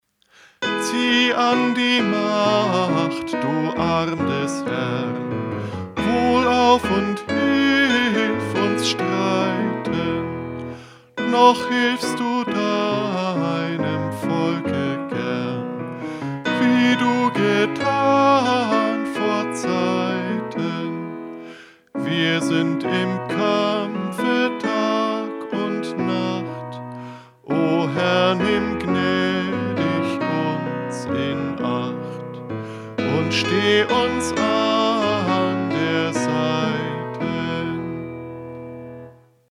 Eingesungen: Liedvortrag (EG 377,